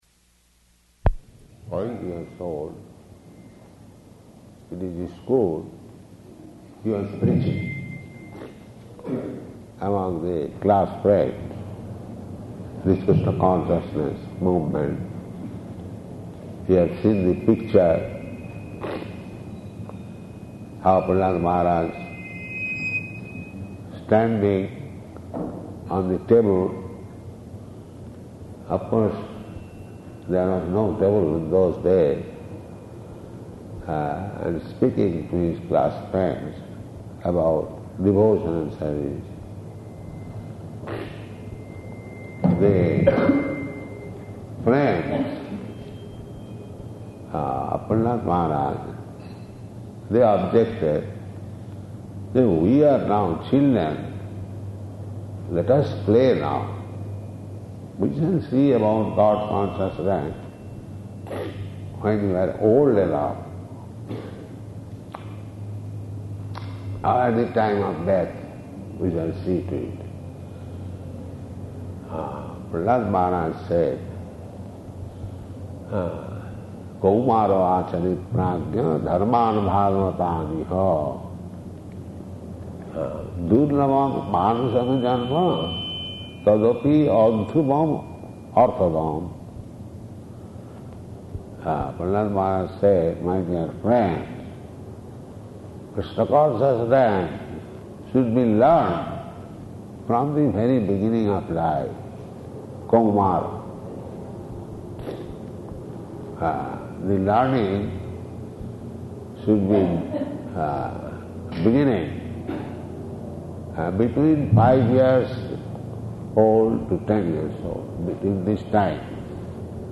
Arrival Address
Arrival Address --:-- --:-- Type: Lectures and Addresses Dated: May 19th 1973 Location: Dallas Audio file: 730519AD.DAL.mp3 Prabhupāda: ....five years old.